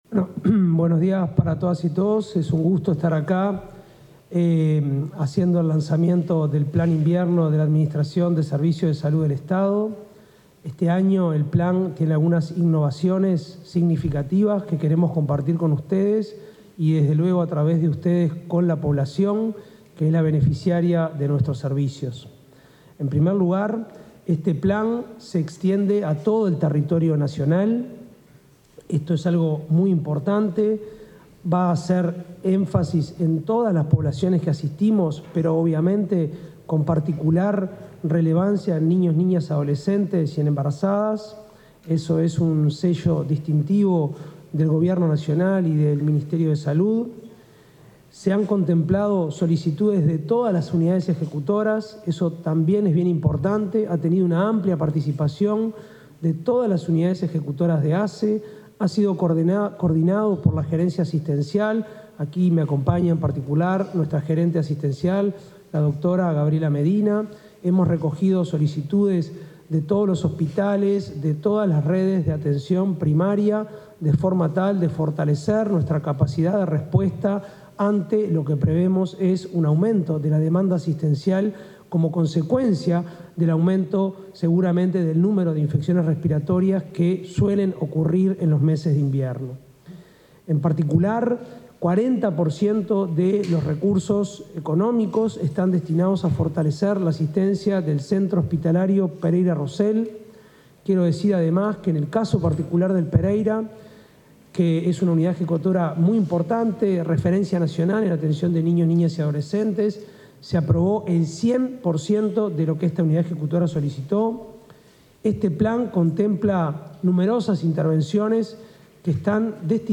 Palabras de autoridades de ASSE y SAME
Palabras de autoridades de ASSE y SAME 11/06/2025 Compartir Facebook X Copiar enlace WhatsApp LinkedIn Durante el lanzamiento del Plan Invierno 2025, se expresaron el presidente de la Administración de los Servicios de Salud del Estado (ASSE), Álvaro Danza, y del director del Sistema de Atención Médica de Emergencia (SAME), Gustavo Grecco.